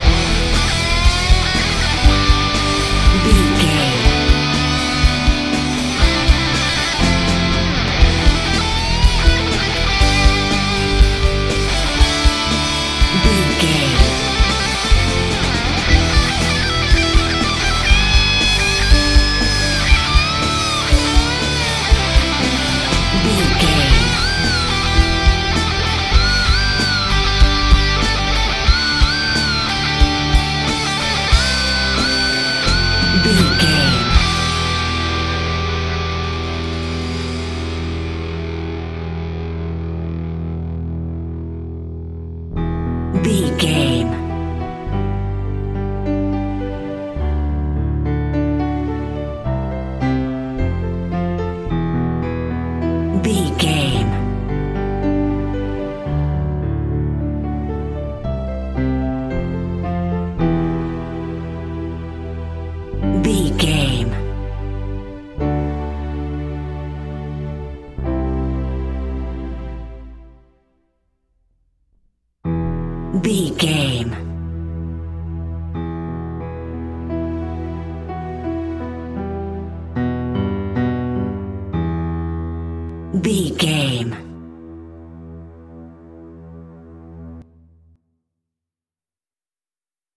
Aeolian/Minor
electric guitar
bass guitar
drums
Sports Rock
hard rock
lead guitar
aggressive
energetic
intense
powerful
nu metal
alternative metal